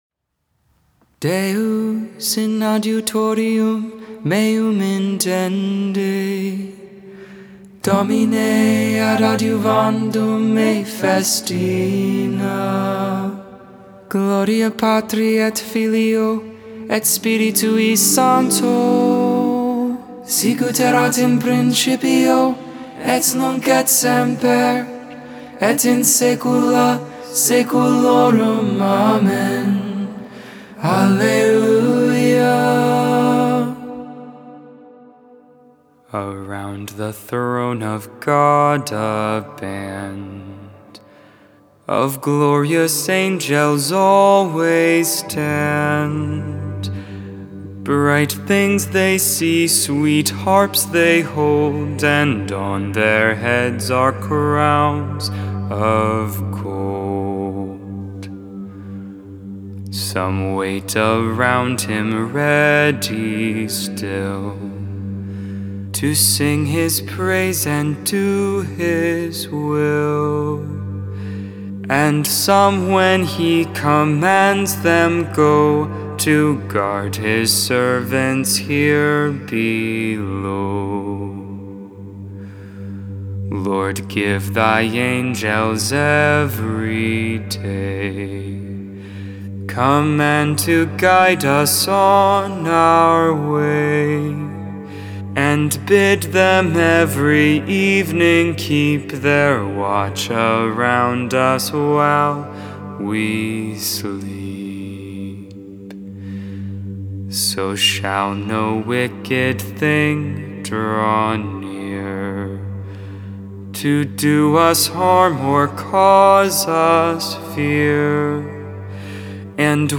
Lauds, Morning Prayer for Saturday of the 26th week in Ordinary Time, October 2nd, 2021, Memorial of the Guardian Angels.